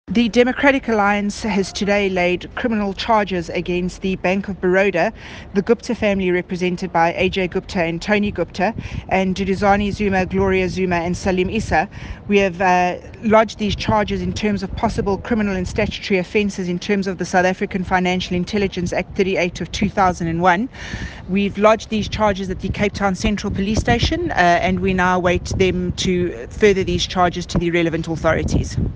English soundbite by Natasha Mazzone MP